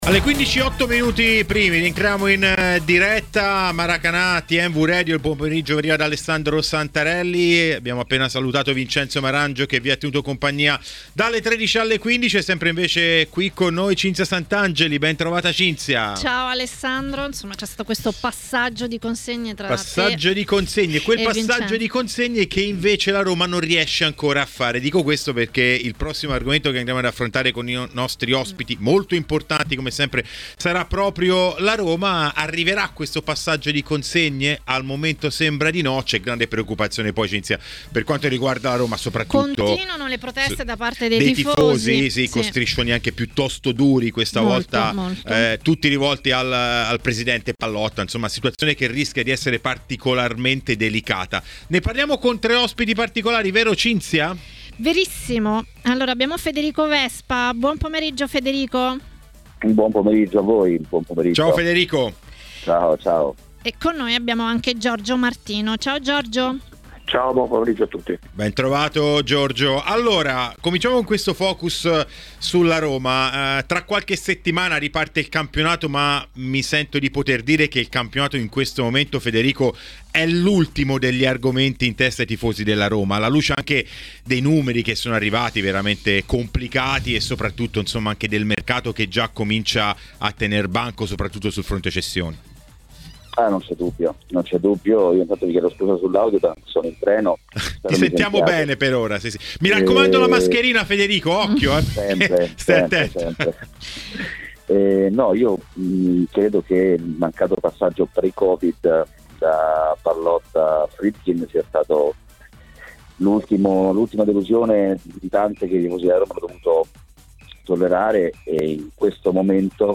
Per parlare di Serie A è intervenuto a Maracanà, nel pomeriggio di TMW Radio